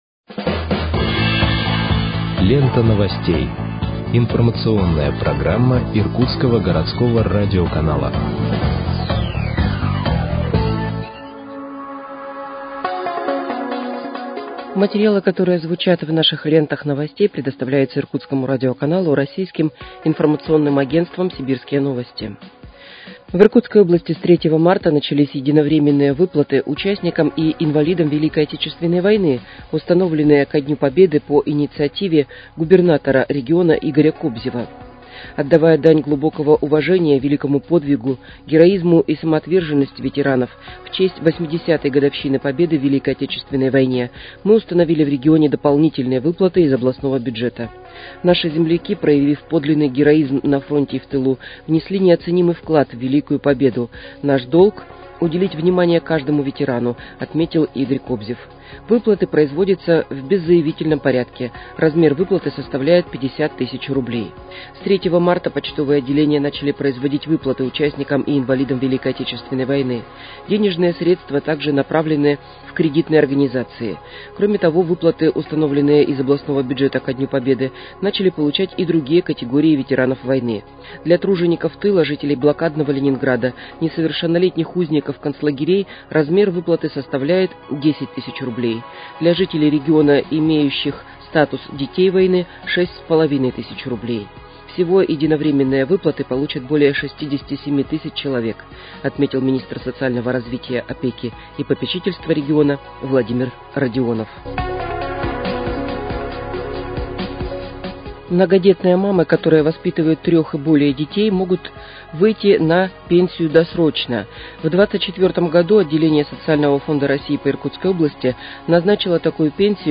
Выпуск новостей в подкастах газеты «Иркутск» от 11.03.2025 № 1